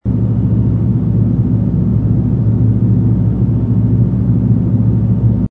ambience_deck_space_larger.wav